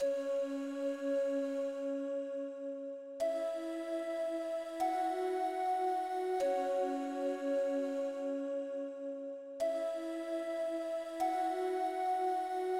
诡异的屁股长笛
标签： 155 bpm Trap Loops Flute Loops 1.04 MB wav Key : D FL Studio
声道立体声